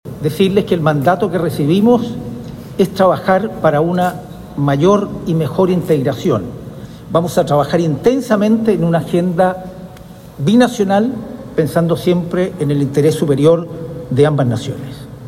Y en el marco de esta gira también se vio al embajador de Chile en Argentina, Gonzalo Uriarte, quien ya está en ejercicio y dio cuenta del mandato que recibió de parte del Presidente Kast.